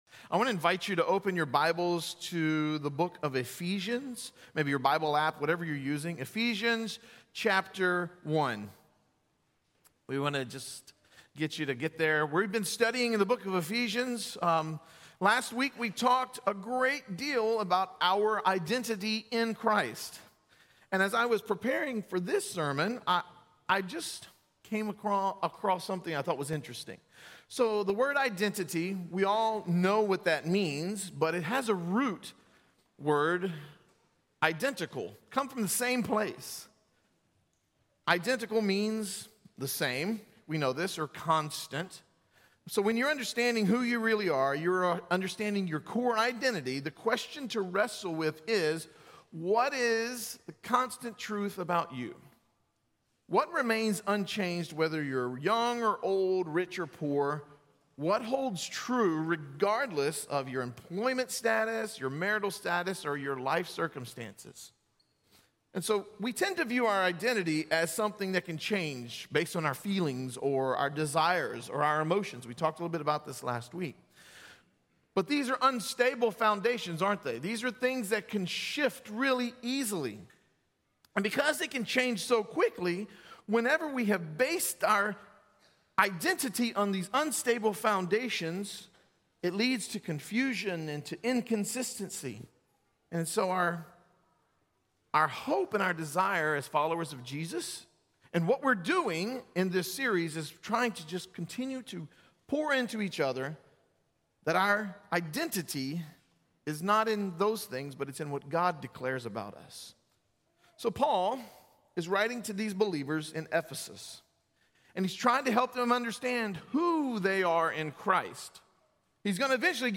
This sermon delves into the theme of being chosen and predestined in Christ, praying for spiritual wisdom and understanding to grasp the hope and inheritance we have in Him.